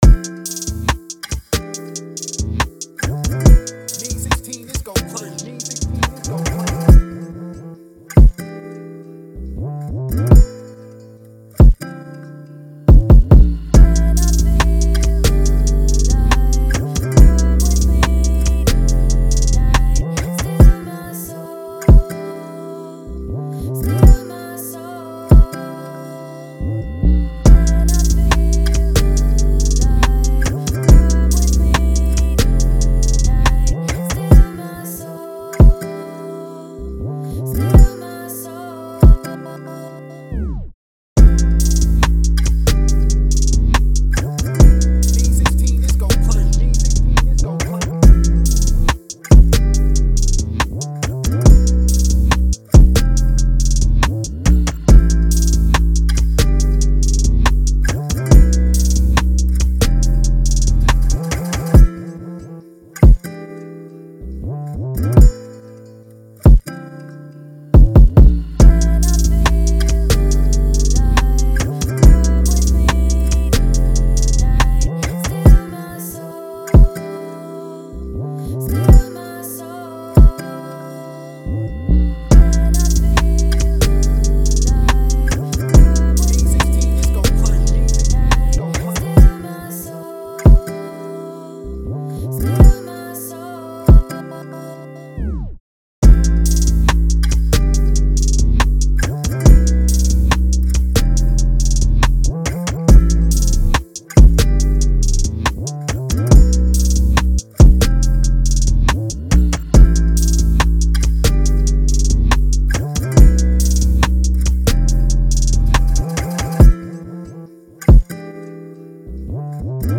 POP
A-Min 140-BPM